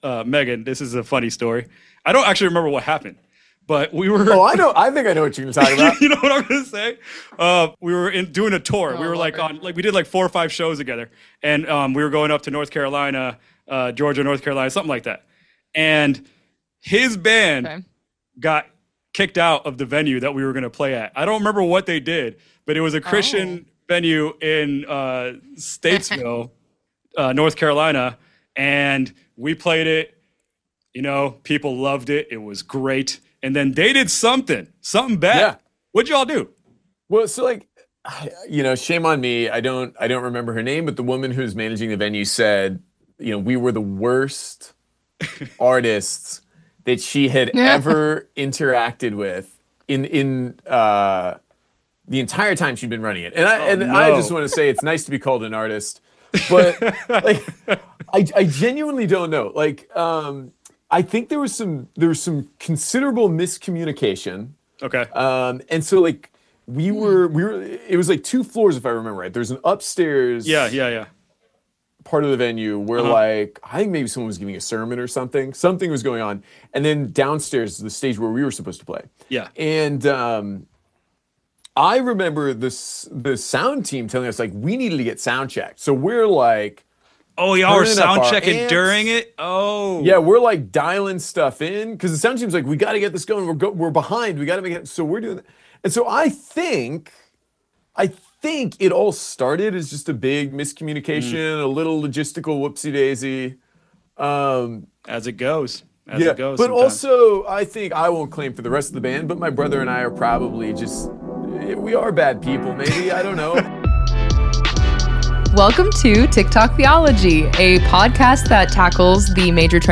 In this episode of the podcast, I interview